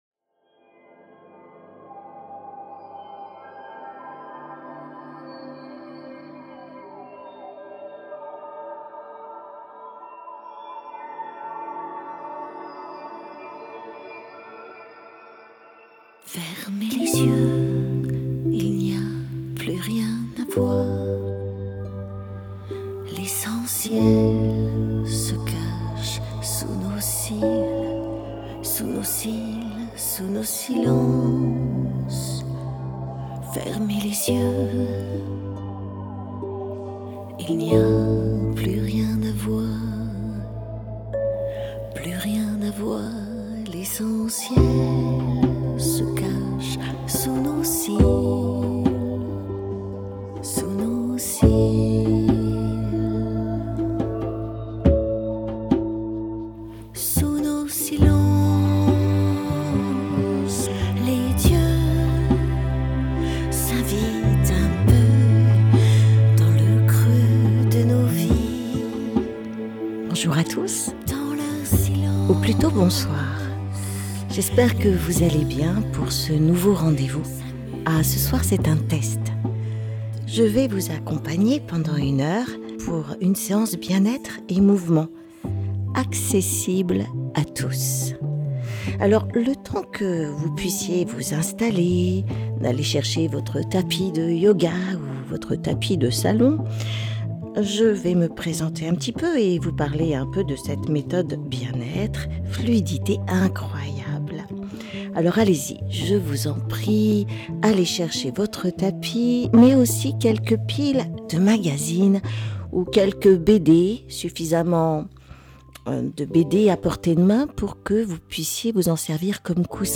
Elle vous propose de découvrir la méthode Feldenkrais et de participer : Une approche douce basée sur le mouvement et la conscience corporelle, pour direadieu à vos douleurs et retirer vos tensions !Vous allez améliorer vos postures,retrouver de l’aisance dans votre corps… et votre esprit Une fois par mois, offrez vous cette pause bienfaisante, en direct sur Antenne 87 !